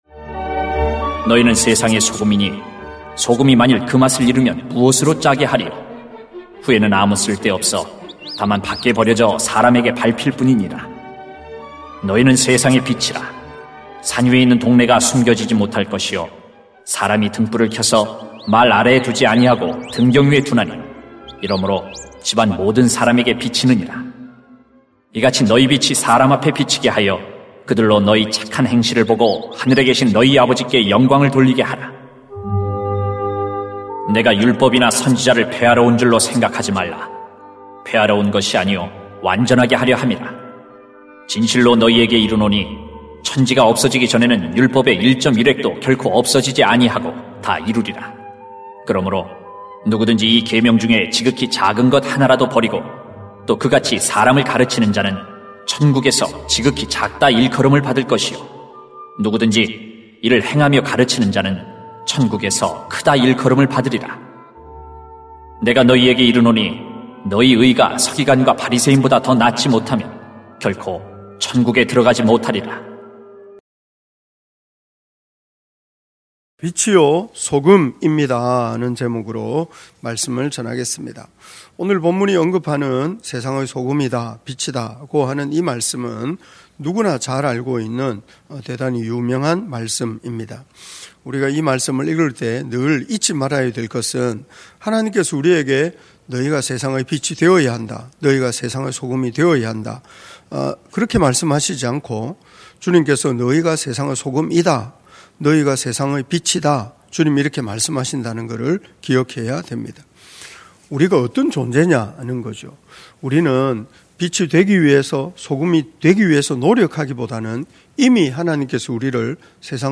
[마 5:13-20] 빛이요 소금입니다 > 새벽기도회 | 전주제자교회